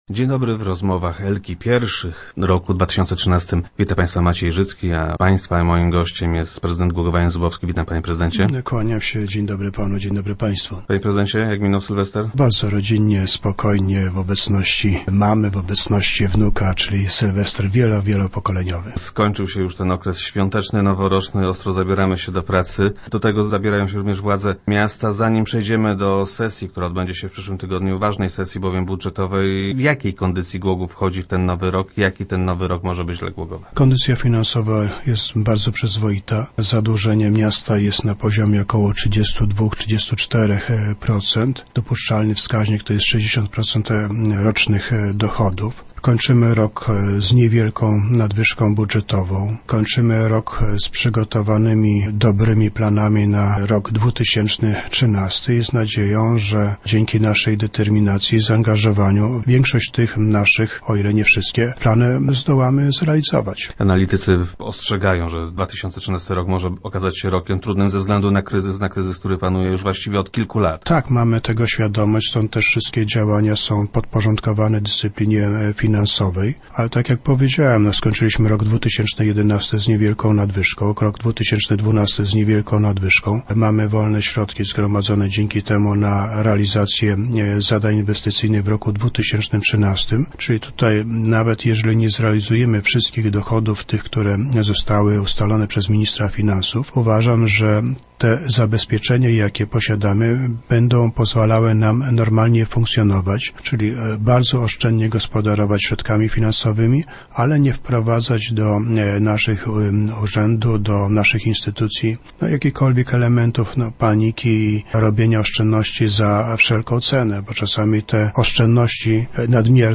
Czy dotknie on także w większym stopniu głogowski samorząd. - Na razie jesteśmy w dobrej kondycji i powinniśmy dać sobie radę – twierdzi prezydent Jan Zubowski, który był gościem pierwszych w tym roku Rozmów Elki.